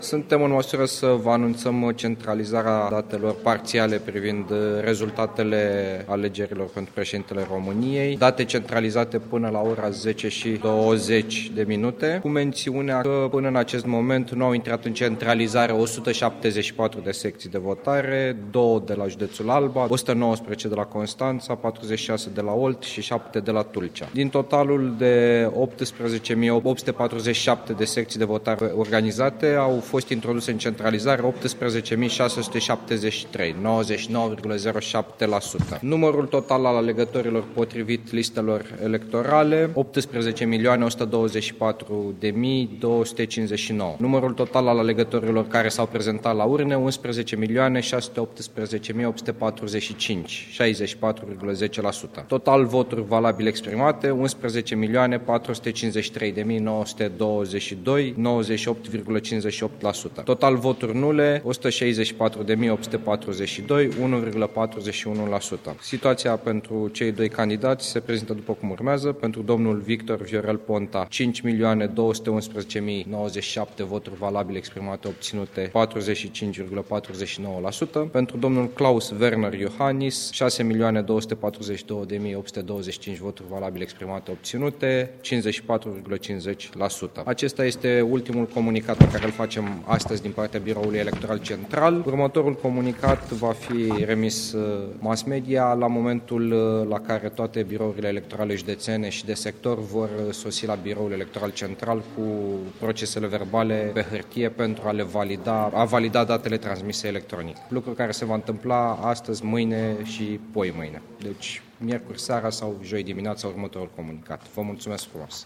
declaratie integrala: